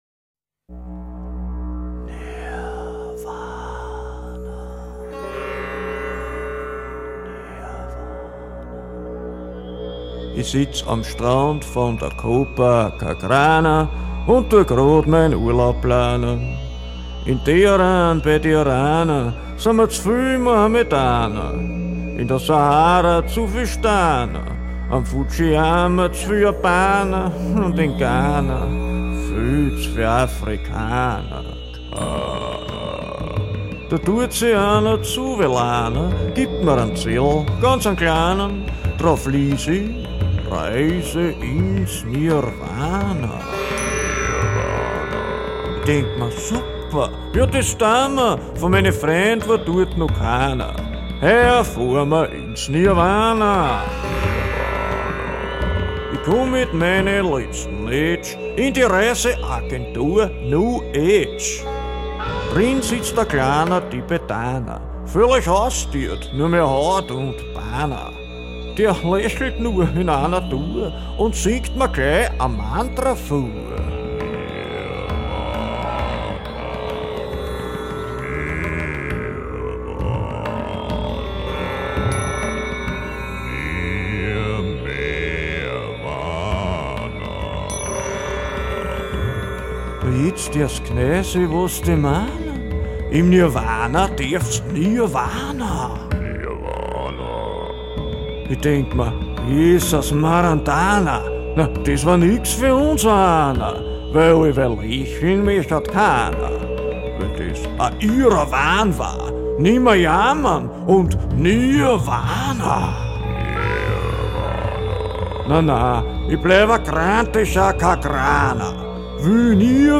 Ein dunkelbunter Liederabend von und mit
Wir hören die dunkelsten und buntesten Früchte langjährigen Liedschaffens, kulinarisch serviert mit Gitarre, Dobro, Mund- und Knöpferlharmonika, Maultrommel, Räptil und Quietschratte - im urigen Sittl-Ambiente - Kleinkunst, angesiedelt zwischen Kabarett und Liedermacherei, an der Grenze von Musik und Literatur.